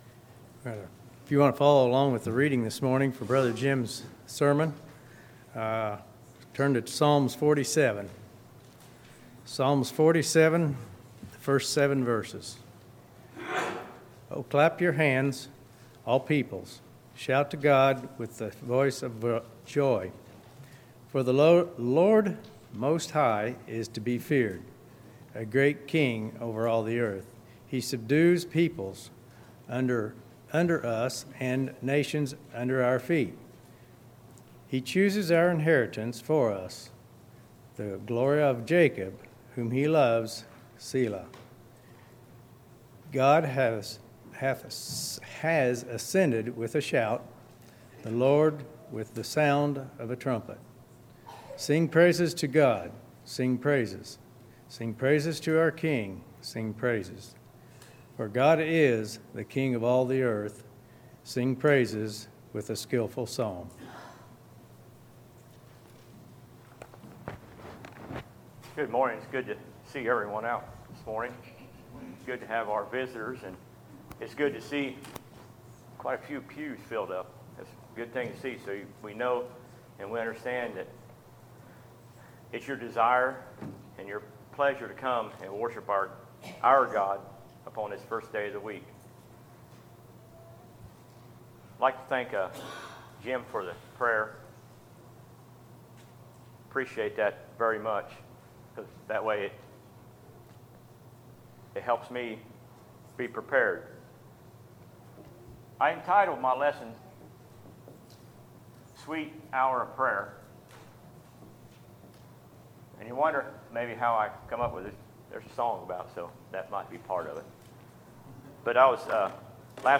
Sermons, August 4, 2019